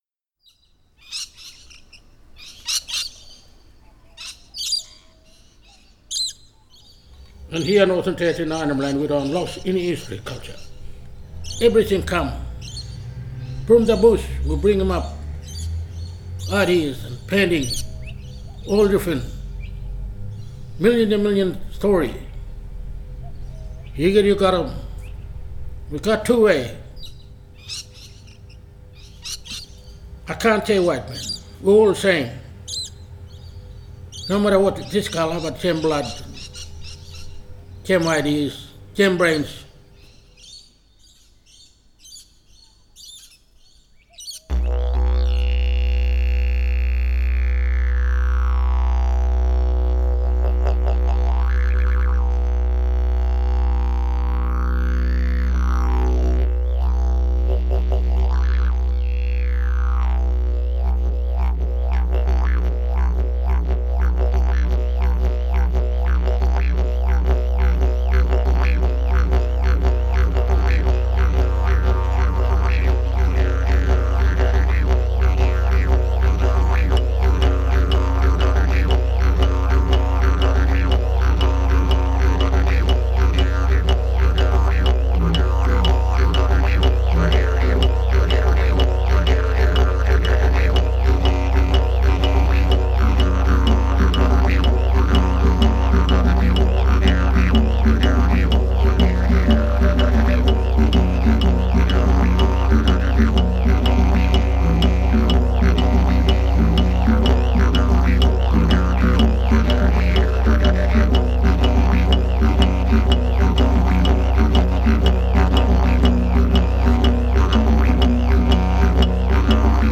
Exemple avec ce m4a de didgeridoo